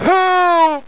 Help! Sound Effect
Download a high-quality help! sound effect.